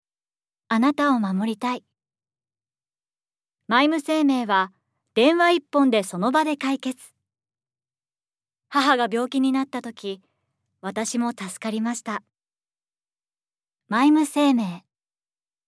ボイスサンプル
ＣＭ